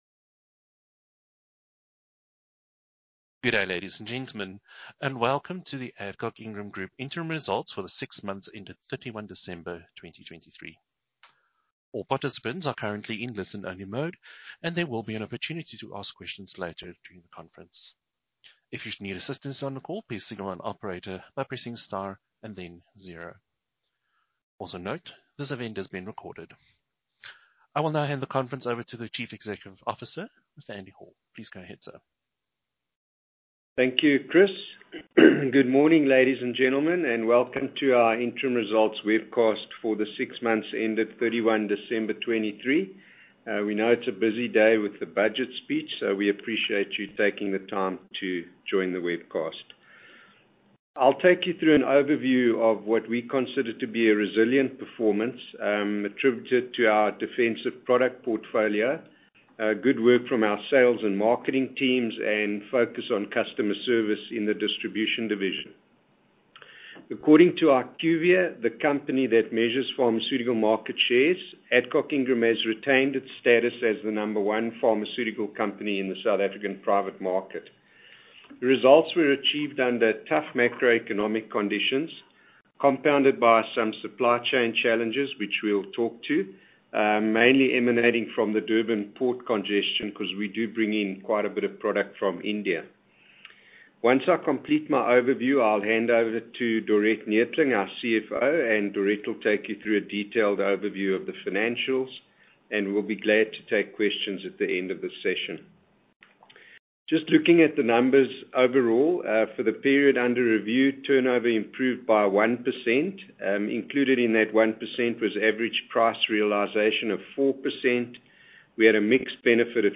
Post Results Conference Call Audio File